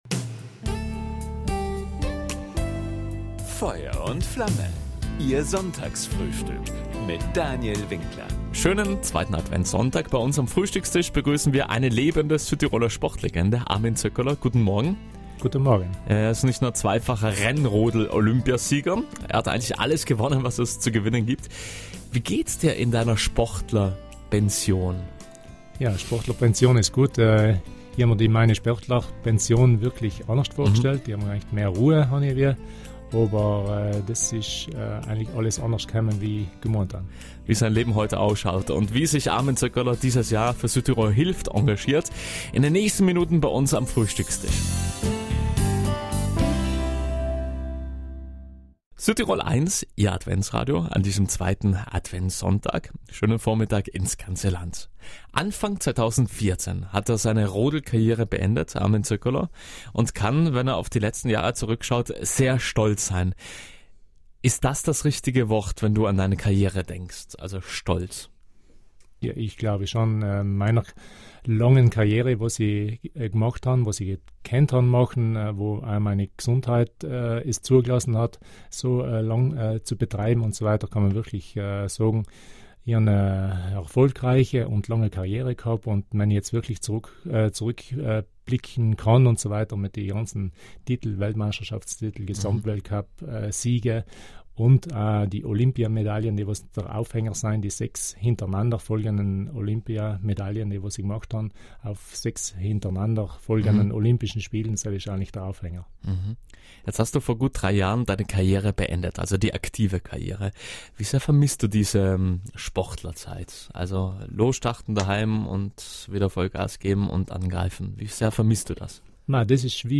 Auch davon hat der Weltklasserodler bei „Feuer und Flamme“ auf Südtirol 1 erzählt.